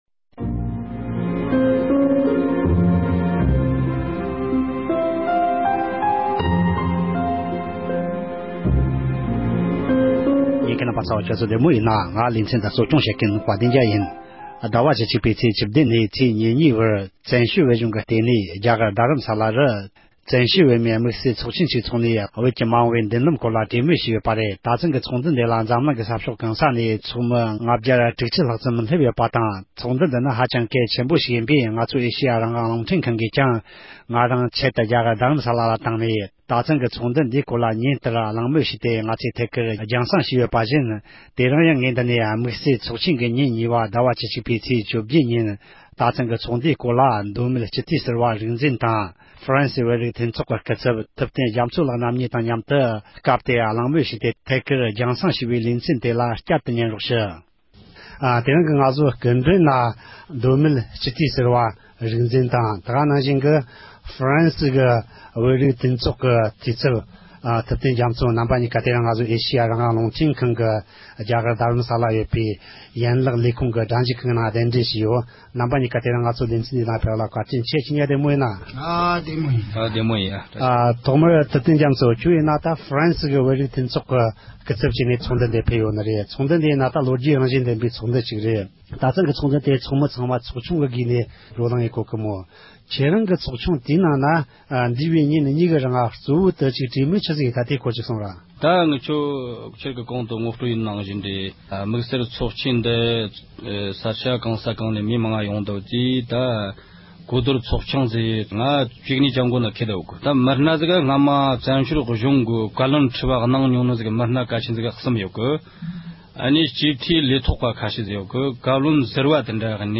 དམིགས་བསལ་ཚོགས་ཆེན་གྱི་ཚོཌ་བཅར་བ་དང་ལྷན་དུ་གླེང་མོལ།
བོད་དོན་དམིགས་བསལ་ཚོགས་ཆེན་ཉིན་མ་གཉིས་པའི་སྐབས་ཚོགས་བཅར་བ་ཁག་དང་ལྷན་དུ་ཚོགས་ཆེན་སྐོར་གླེང་མོལ་ཞུས་ཡོད་པ།